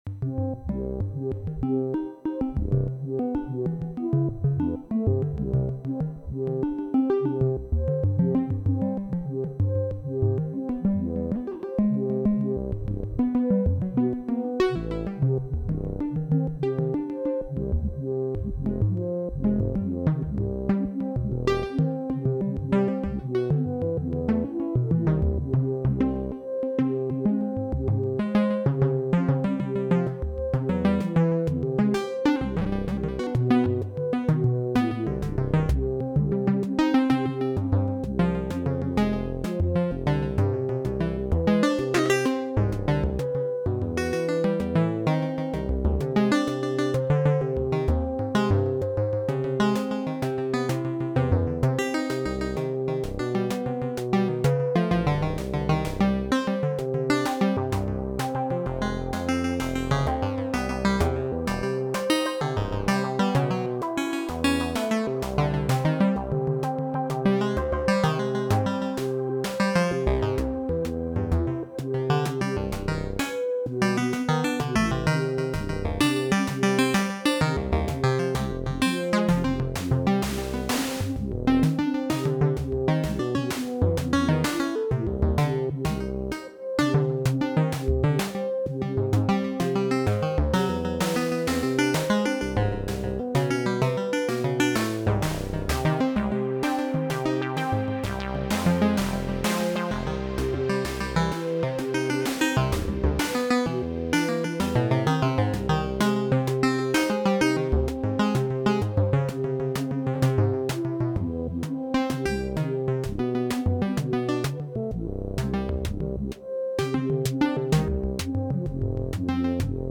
Labyrinth, Subharmonicon and DFAM through Valhalla Supermassive